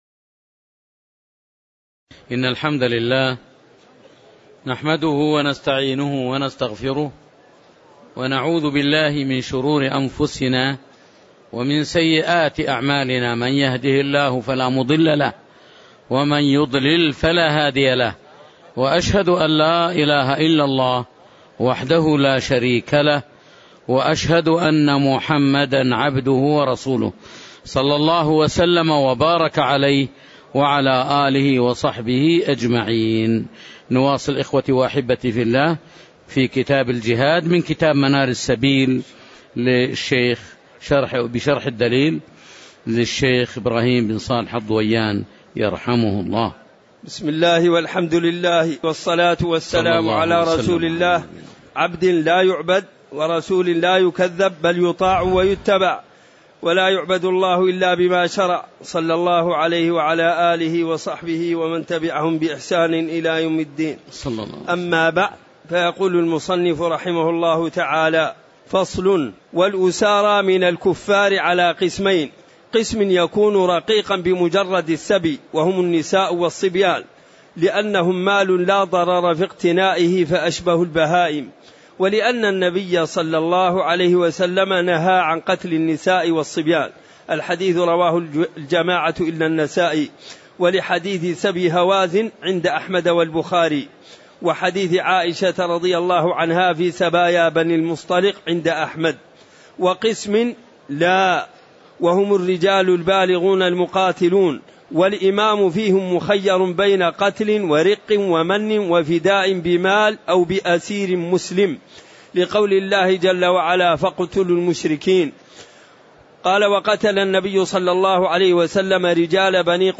تاريخ النشر ٢٥ ذو الحجة ١٤٣٩ هـ المكان: المسجد النبوي الشيخ